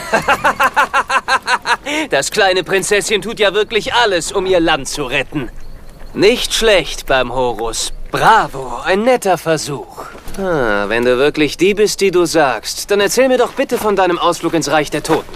Die gute deutsche Bearbeitung trägt viel zur Unterhaltung bei.